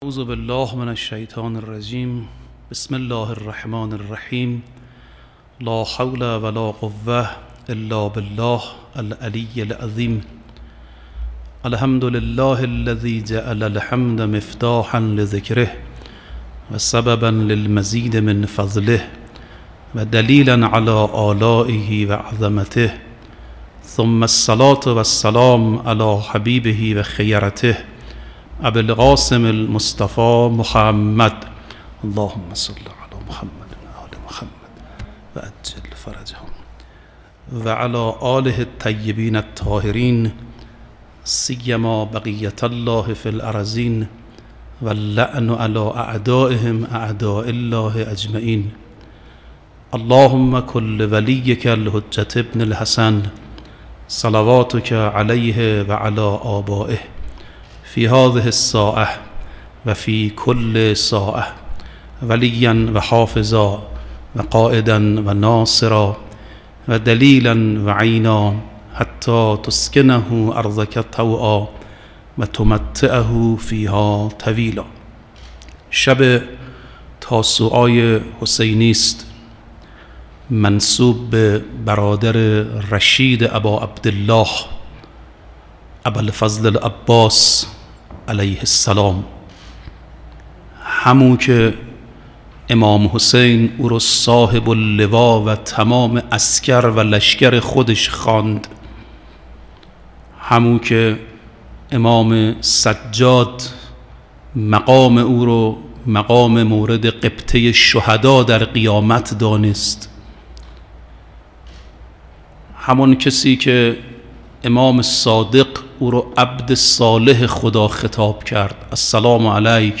به گزارش پایگاه اطلاع رسانی هیات رزمندگان اسلام، مراسم عزاداری شب تاسوعا در حسینیه‌ی امام خمینی(ره) با حضور امام خامنه ای برگزار شد.
سخنرانی شب تاسوعای حسینی-محرم1442-1399 پایگاه اطلاع رسانی هیات رزمندگان اسلام صوت های سخنرانی سخنرانان مطرح کشوری را برای شما همراهان در سایت هیات جمع آوری نموده است.